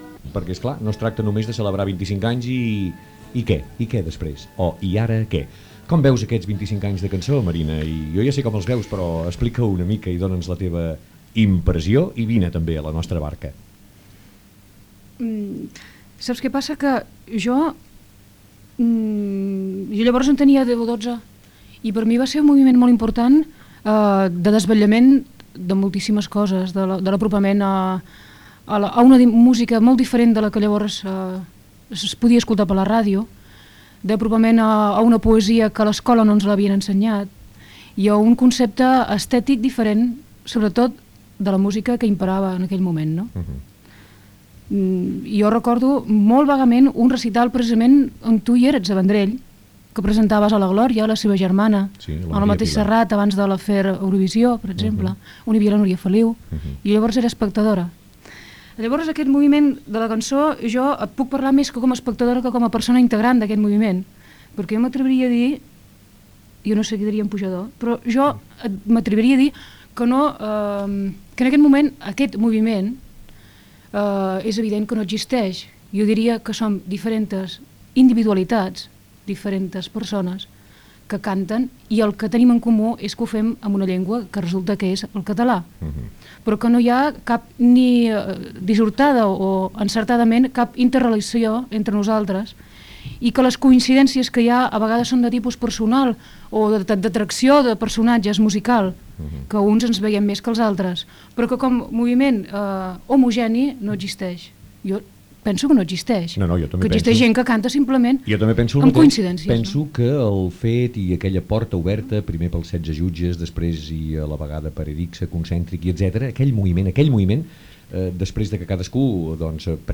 Conversa al voltant dels 25 anys de la Nova Cançó amb la cantant Marina Rossell i de com és, en aquell moment, el moviment de cantar en català.
Entreteniment